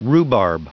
Prononciation du mot rhubarb en anglais (fichier audio)
Vous êtes ici : Cours d'anglais > Outils | Audio/Vidéo > Lire un mot à haute voix > Lire le mot rhubarb